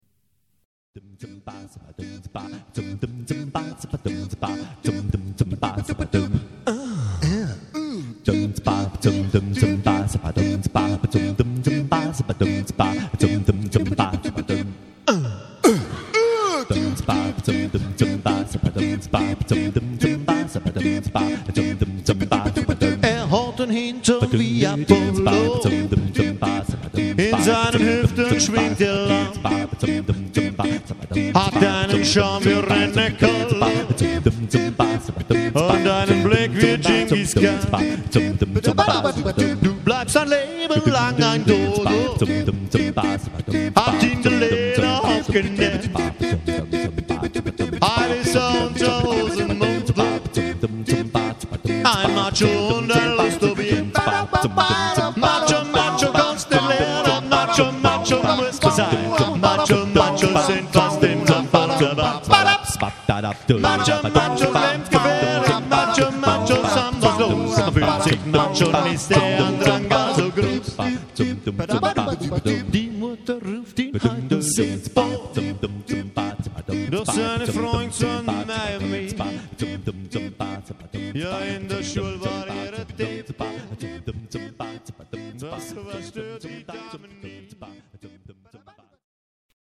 A-Cappella
live & authentisch.